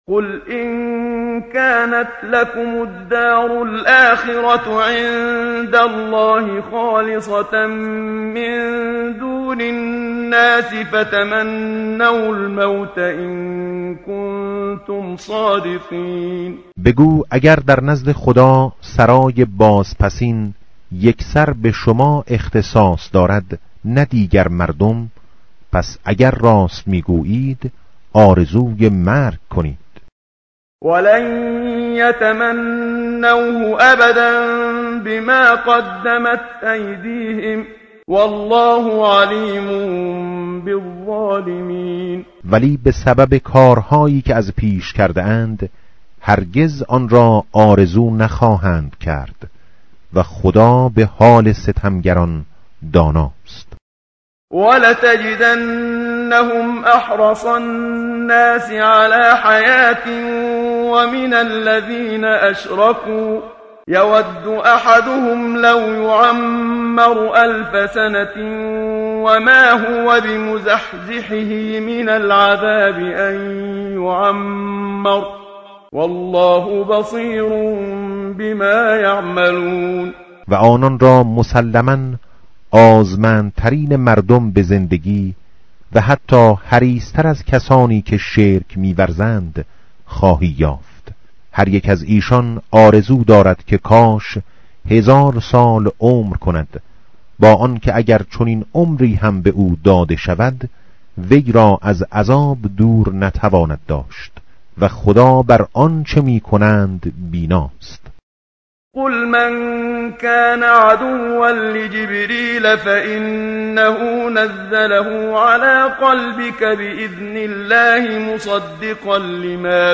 متن قرآن همراه باتلاوت قرآن و ترجمه
tartil_menshavi va tarjome_Page_015.mp3